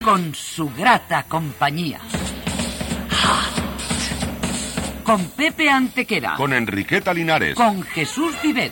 Promoció del programa, amb els noms de l'equip